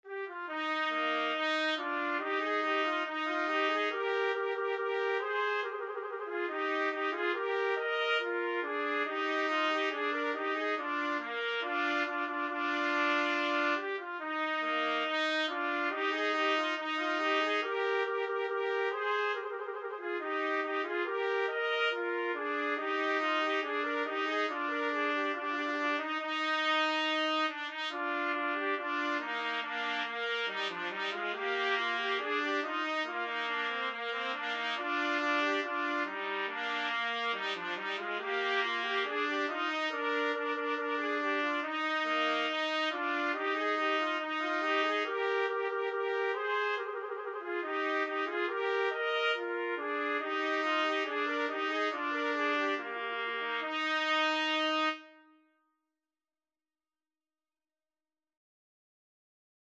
2/2 (View more 2/2 Music)
Allegro Maestoso = 70 (View more music marked Allegro)
Trumpet Duet  (View more Easy Trumpet Duet Music)
Classical (View more Classical Trumpet Duet Music)